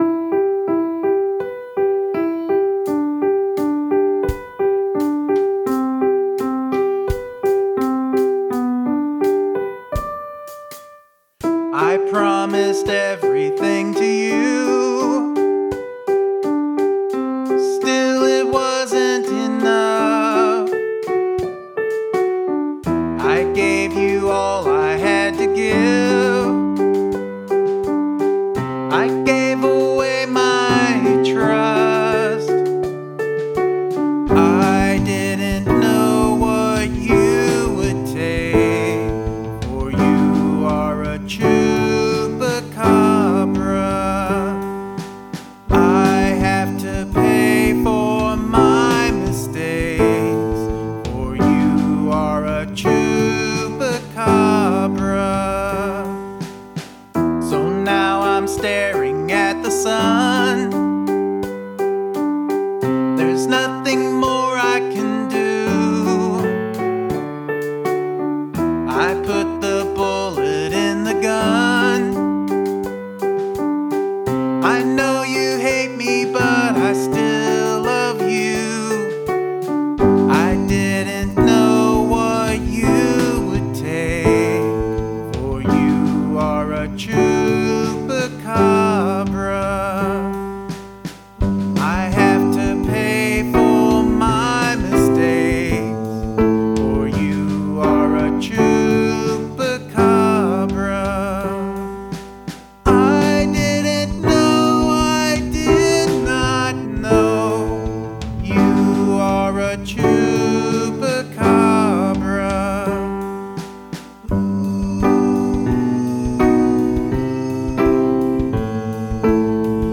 Use exactly two instruments (plus vocals) to create your song.
I like the really minimalistic single-note piano that starts out the song--I think it’s unusual and effective and actually loses a bit of power once the chords come in (although it probably wouldn’t work all the way through without a break or rhythmic shift at some point).